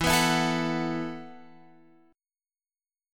E Suspended 2nd Suspended 4th